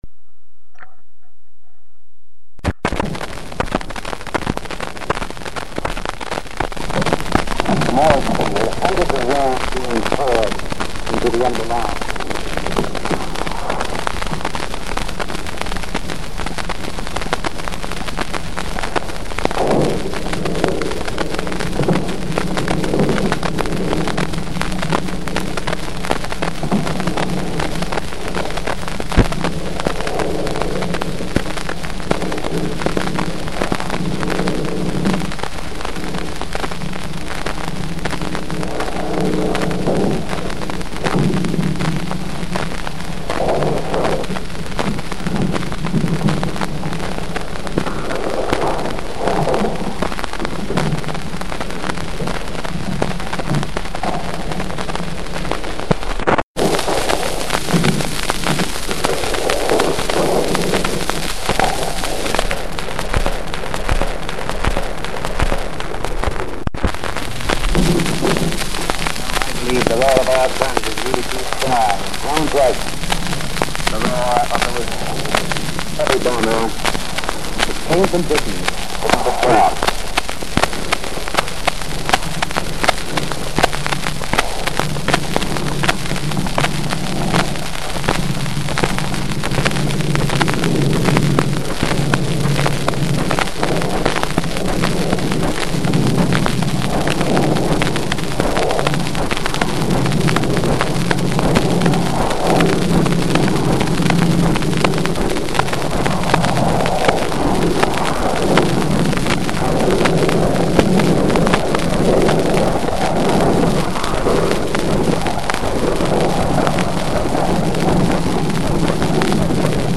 However this one record had weird sounds I thought at first was a thunderstorm.
What I was listening to was a LIVE recording of the BOMBS during the invasion of Normandy.
The Recordio disk was in bad shape and the other side nearly unplayable as it had been wet and stuck to its sleeve wrapper.